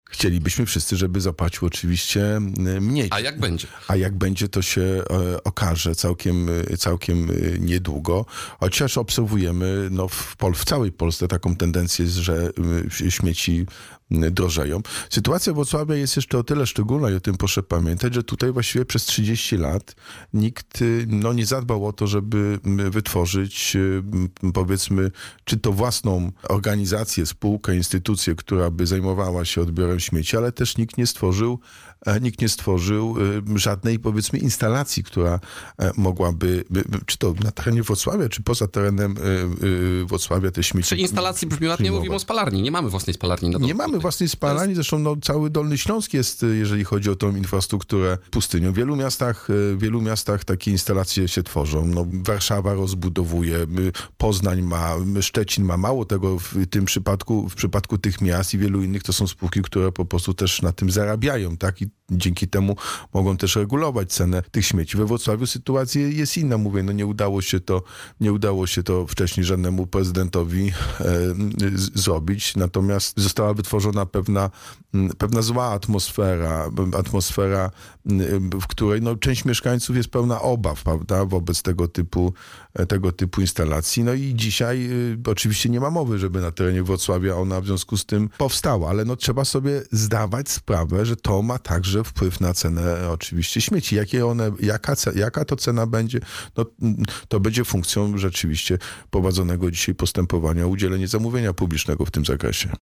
– Staram się szukać przebaczenia i wybaczać nawet tym, którzy czynią mi źle – powiedział w rozmowie z Radiem Rodzina prezydent Wrocławia, Jacek Sutryk.